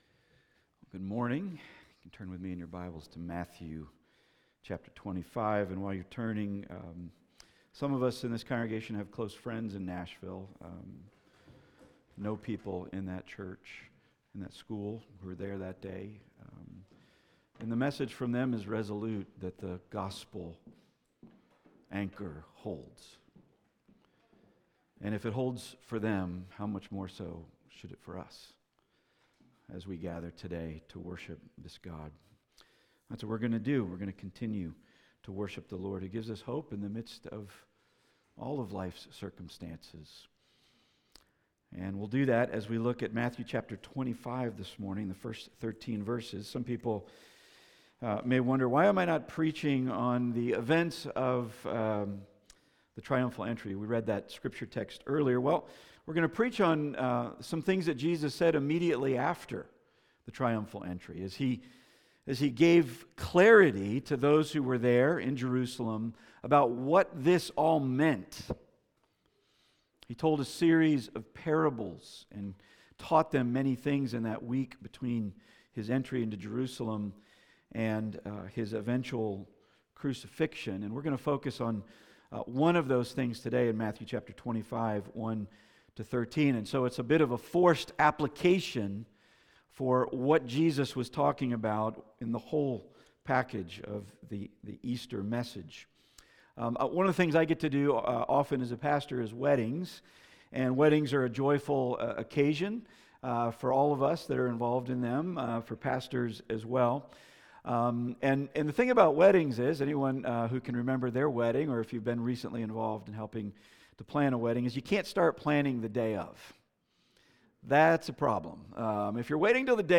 Passage: Matthew 25:1-13 Service Type: Weekly Sunday